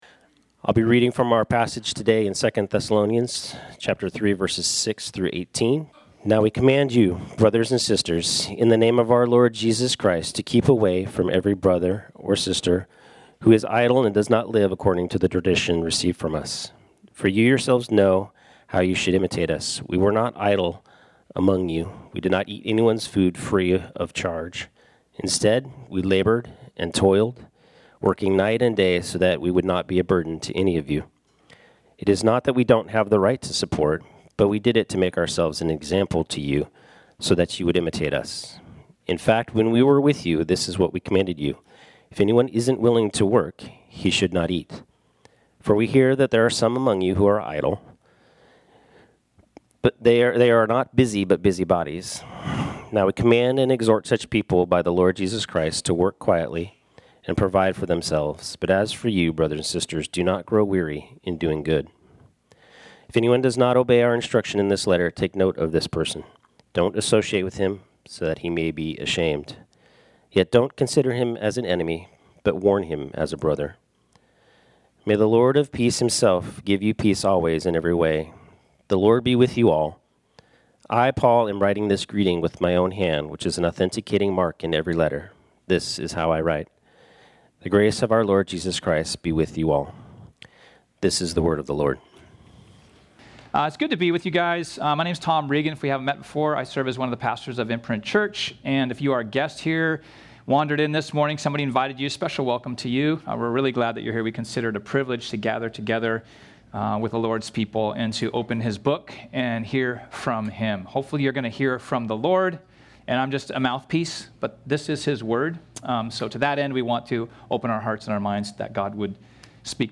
This sermon was originally preached on Sunday, September 28, 2025.